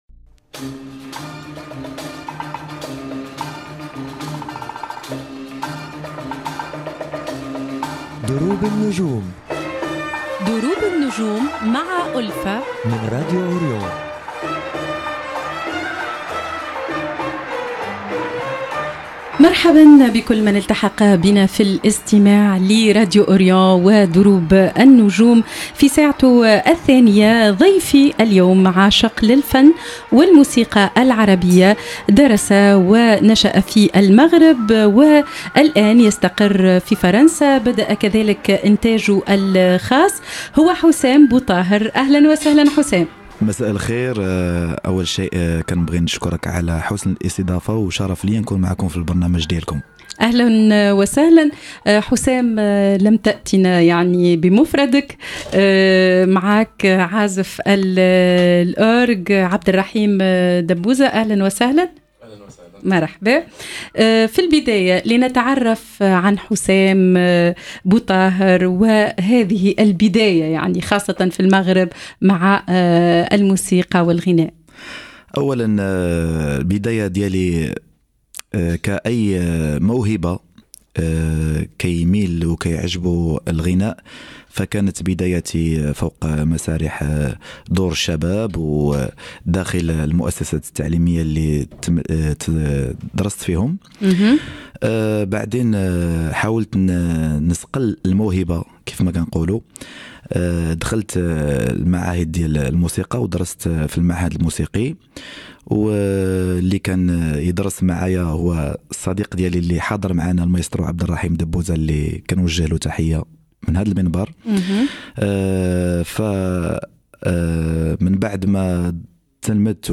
اللقاء تحوّل إلى مساحة من التفاعل والغناء الحي، تبادل خلالها الفنانان الآراء والتجارب، في حوار جمع بين جيلين من الإبداع والحنين.